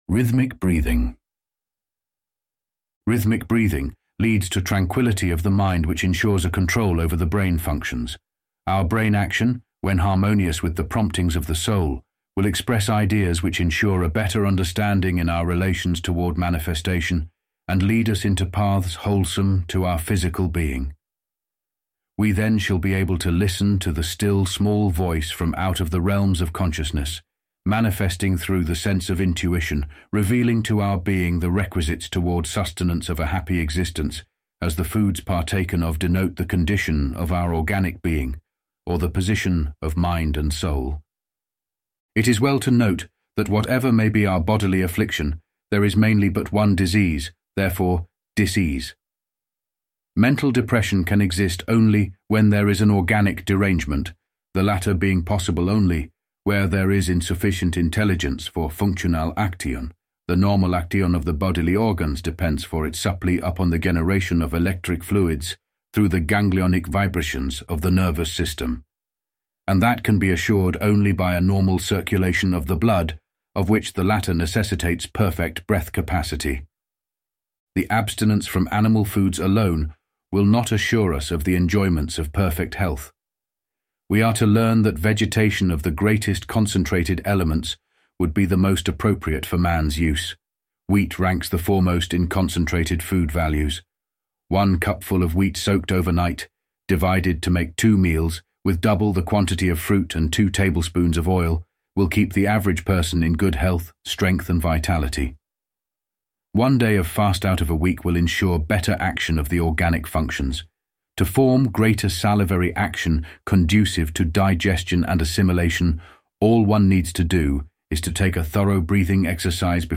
(spoken by Elevenlabs Greg)